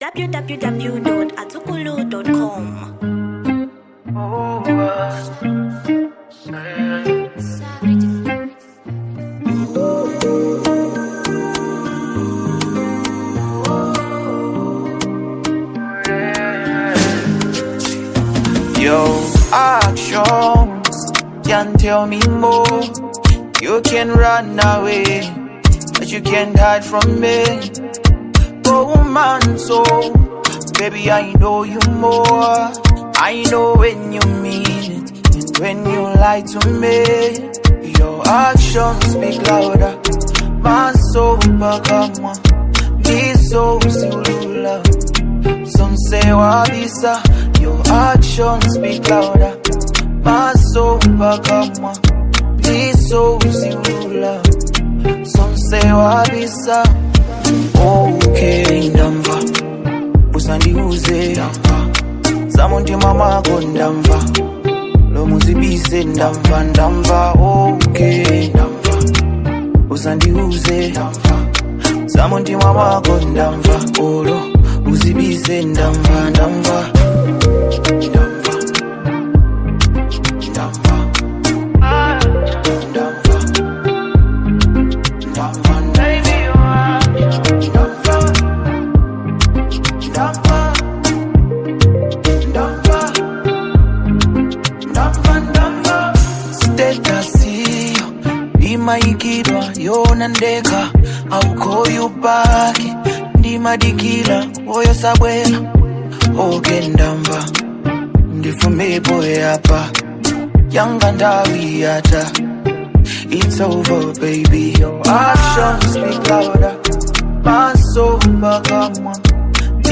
Genre Afrobeat